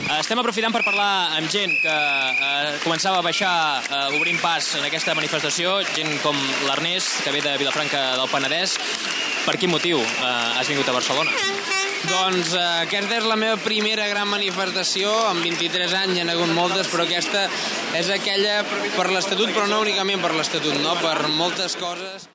Informació des de la capçalera de la manifestació «Som una nació. Nosaltres decidim» en contra de la resolució del recurs d'inconstitucionalitat efectuat pel Tribunal Constitucional d'Espanya sobre el text de l'Estatut de Catalunya.
Declaració d'un dels manifestants.
Informatiu